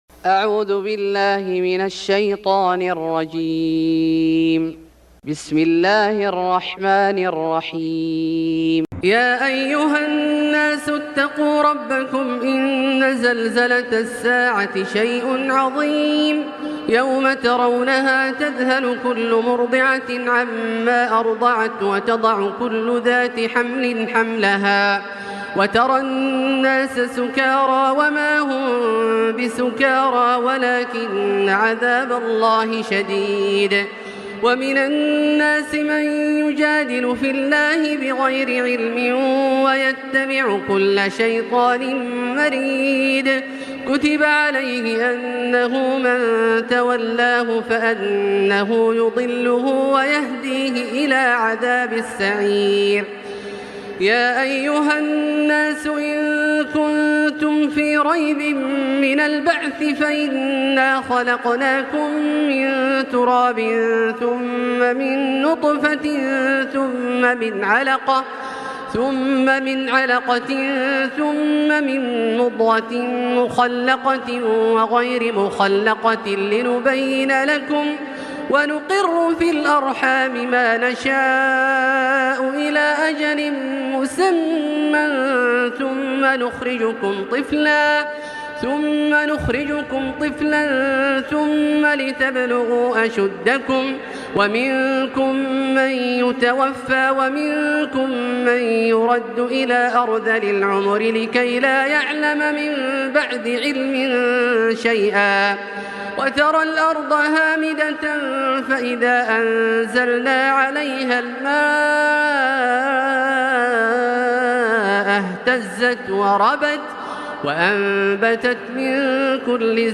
سورة الحج Surat Al-Hajj > مصحف الشيخ عبدالله الجهني من الحرم المكي > المصحف - تلاوات الحرمين